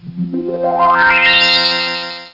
Cosmic Opening Sound Effect
Download a high-quality cosmic opening sound effect.